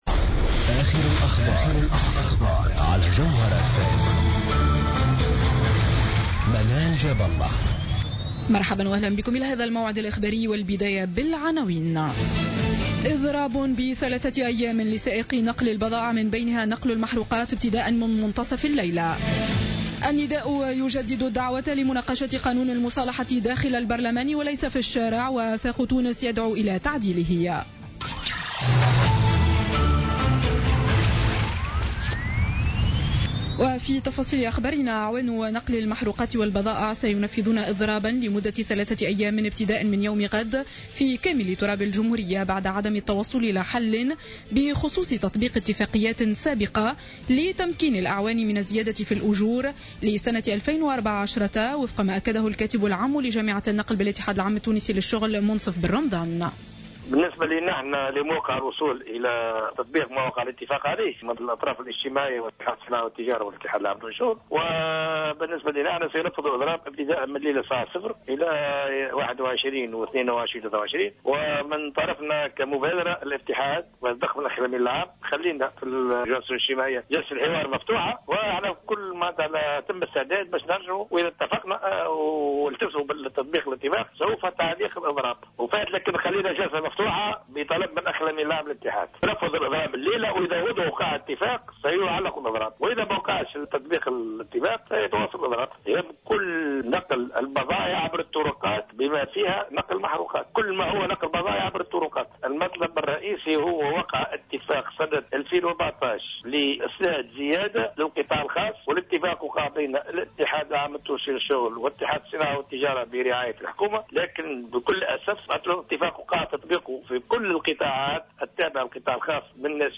نشرة أخبار السابعة مساء ليوم الأحد 20 سبتمبر 2015